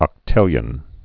(ŏk-tĭlyən)